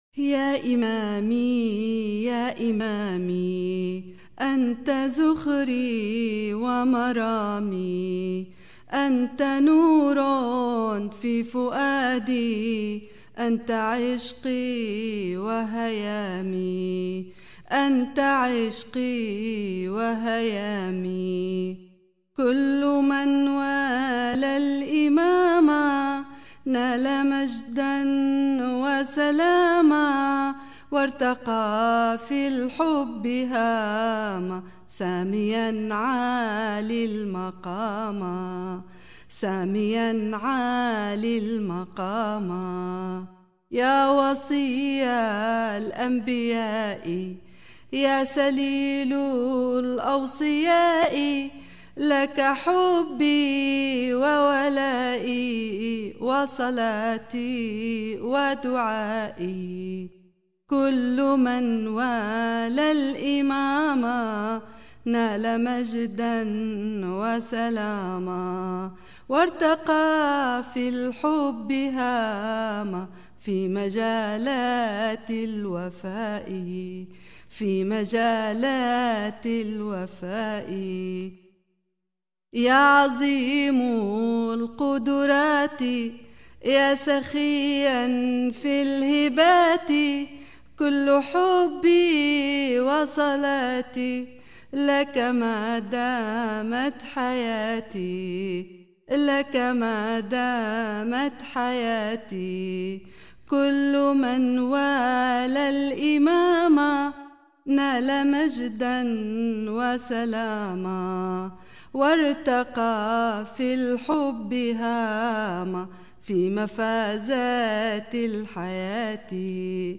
Qasida: Ya Imami Ya Imami – O my Imam, O my Imam
Audio (Anasheed Dinya)